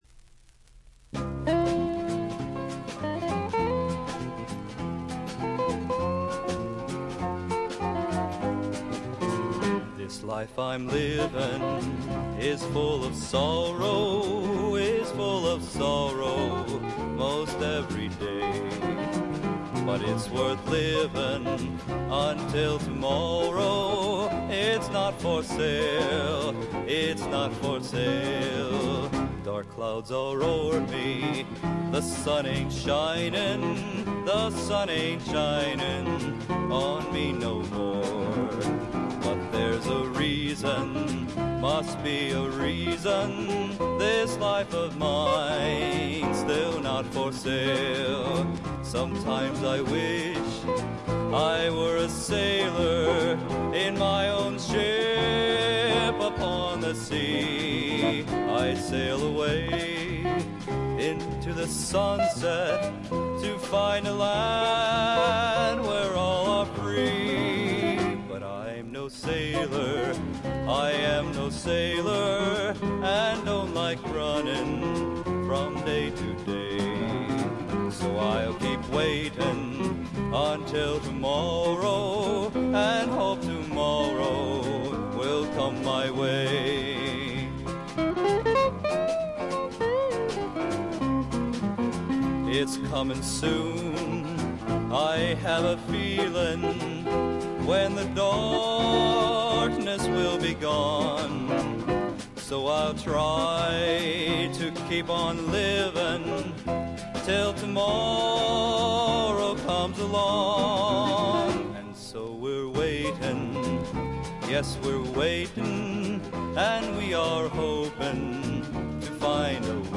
クールでちょっとジャジーな感覚が漂う60年代ポップな作品に仕上がっています。
ヴォーカルも優しさあふれるちょっと低音の魅力がいい感じ・・・
試聴曲は現品からの取り込み音源です。